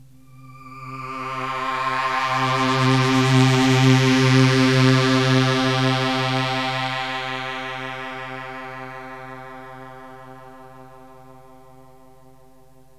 AMBIENT ATMOSPHERES-2 0004.wav